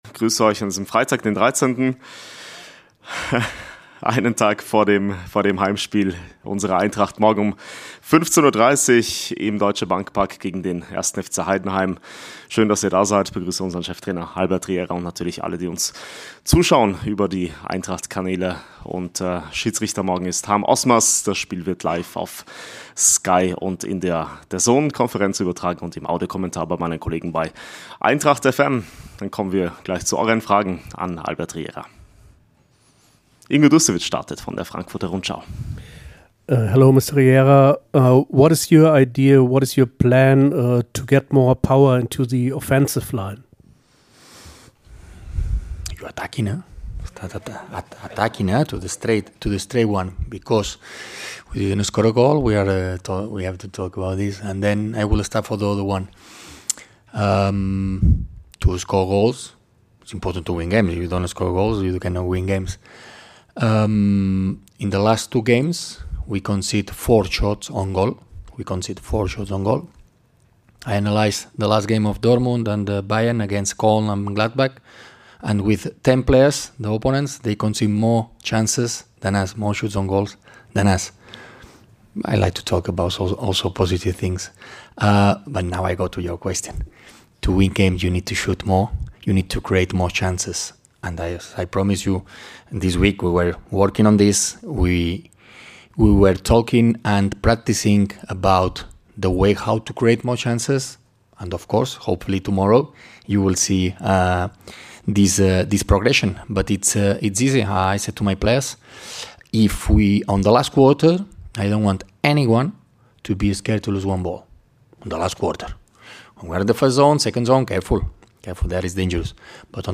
Die Pressekonferenz vor dem Bundesliga-Heimspiel mit Cheftrainer Albert Riera gibt es im EintrachtTV-Livestream, präsentiert von Generali.